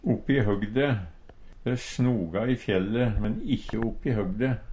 oppi høgde - Numedalsmål (en-US)